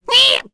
voices / heroes / en
Lakrak-Vox_Attack4.wav